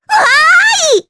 Rephy-Vox_Happy4_jp.wav